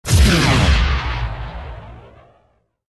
.开火4.ogg